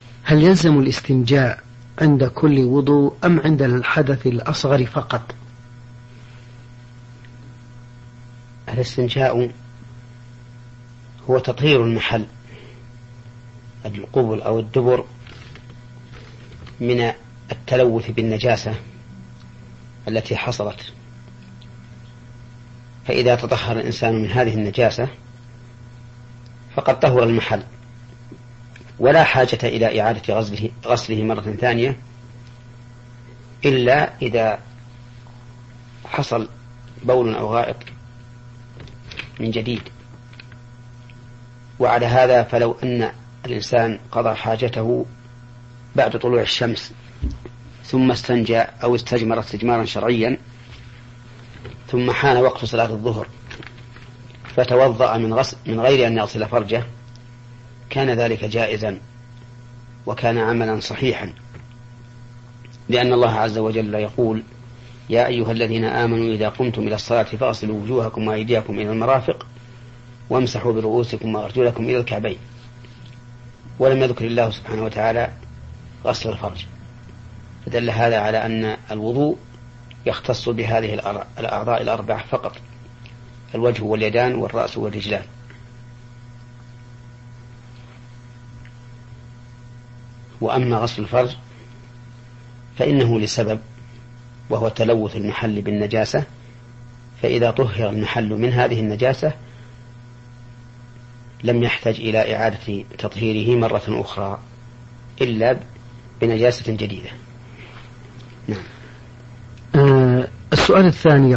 💥 فضيلة الشيـــــخ العلامة الفقيه : محمد بن صالح العثيمين رحمه الله